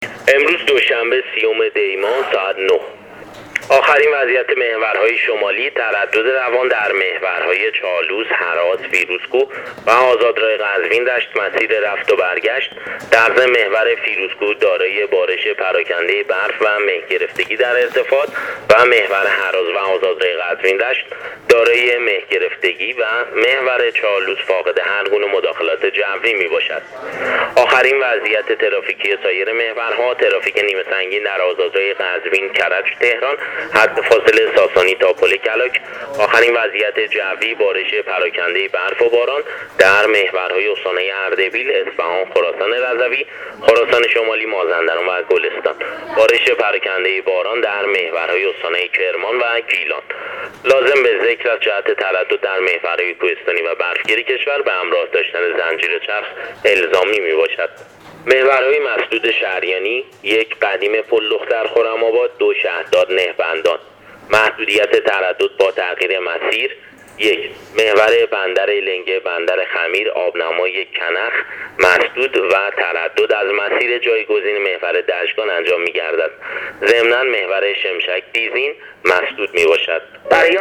گزارش رادیو اینترنتی از آخرین وضعیت ترافیکی جاده‌ها تا ساعت ۹ دوشنبه ۳۰دی‌ماه ۱۳۹۸: